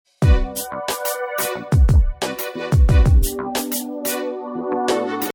Tag: 101 bpm Chill Out Loops Pad Loops 3.20 MB wav Key : Unknown